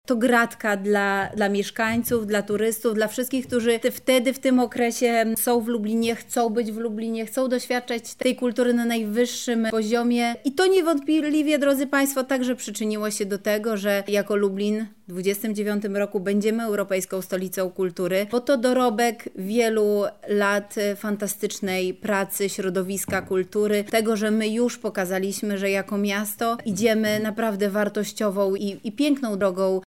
Beata Stepaniuk-Kuśmierzak, zastępczyni Prezydenta Miasta Lublin do spraw Kultury, Sportu i Partycypacji